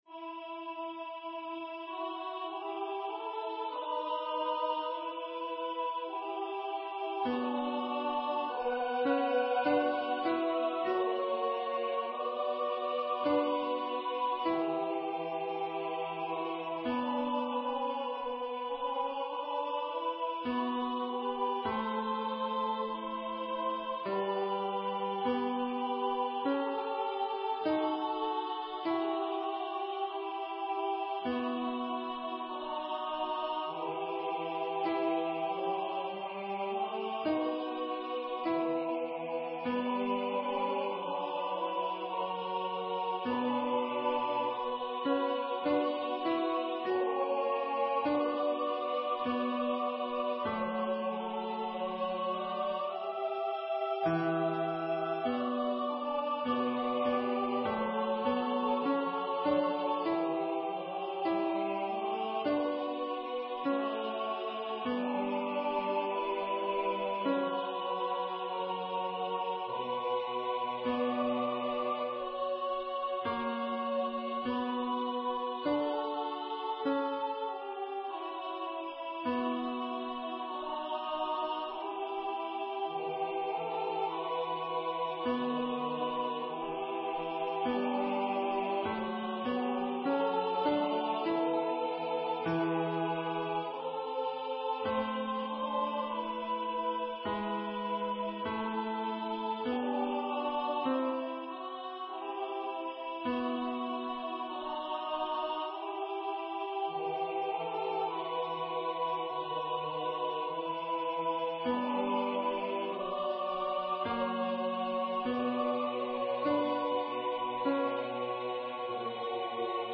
with Accompaniment
MP3 Practice Files: Soprano:   Alto:   Tenor:
Number of voices: 4vv   Voicing: SATB
Genre: SacredMass